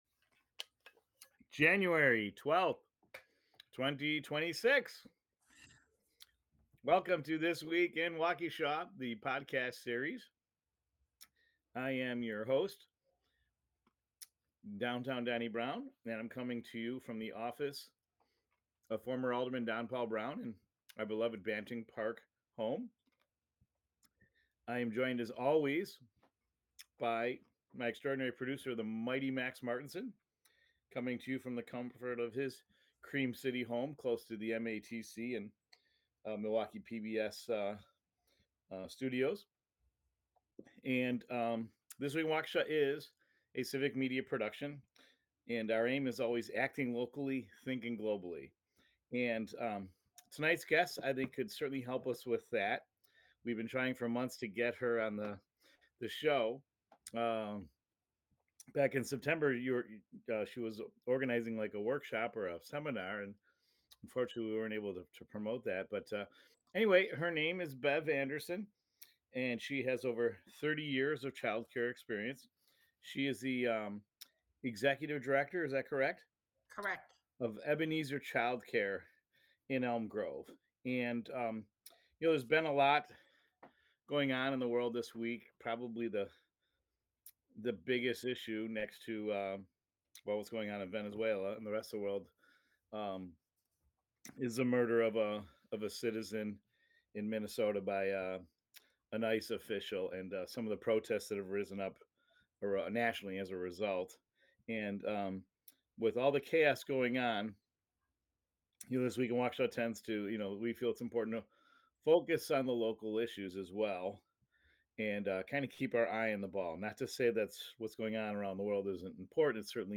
for an in-depth conversation on the state of child care in Wisconsin.